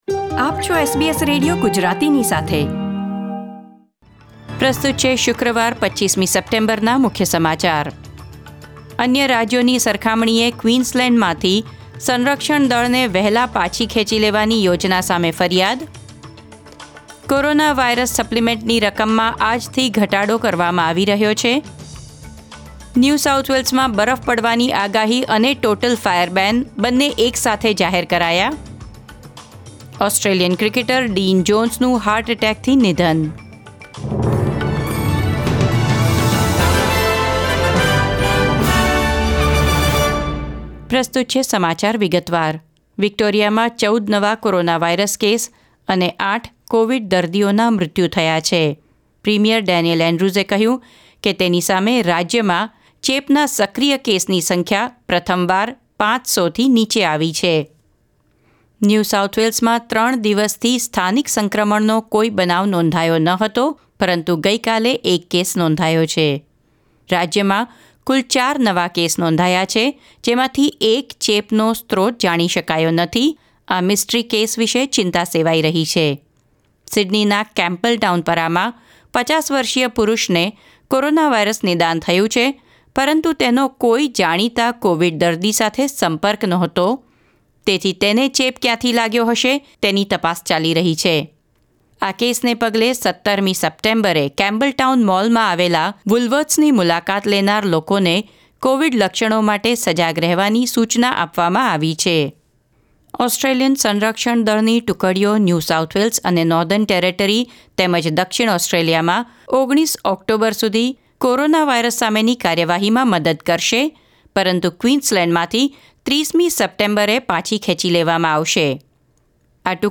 SBS Gujarati News Bulletin 25 September 2020